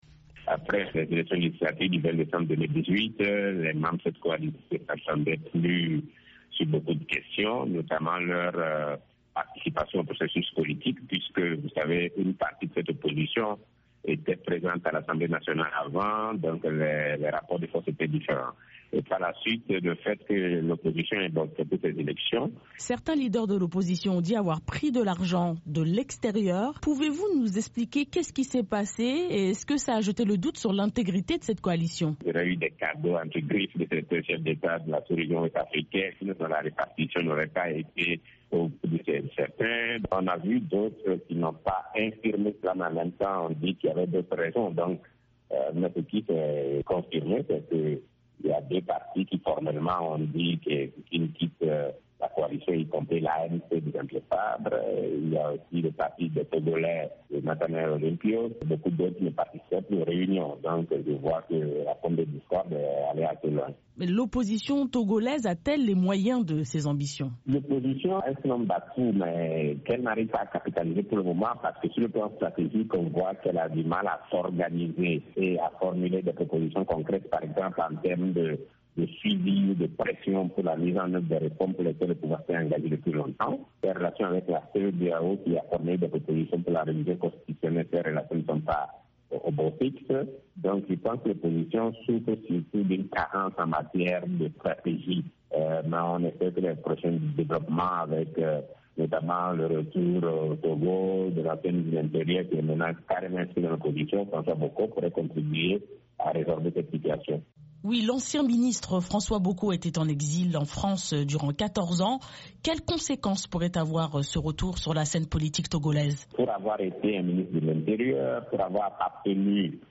analyste politique et membre de la société civile togolaise.